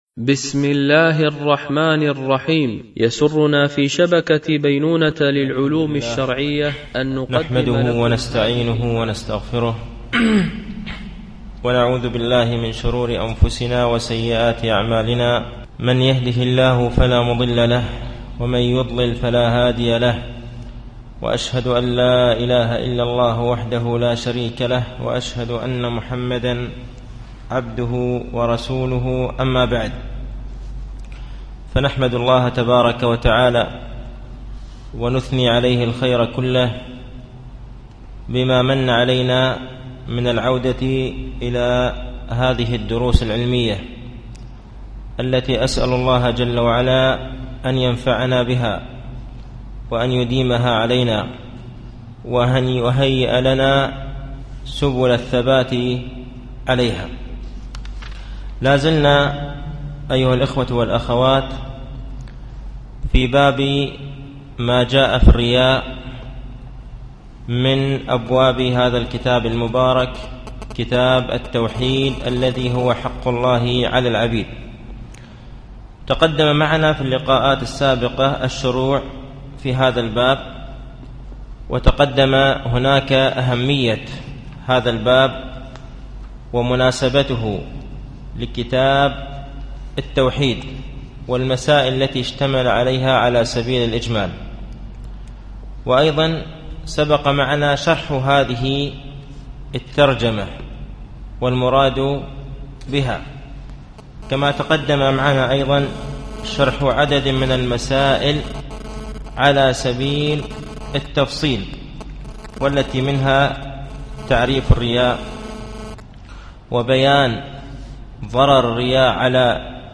التنسيق: MP3 Mono 22kHz 62Kbps (VBR)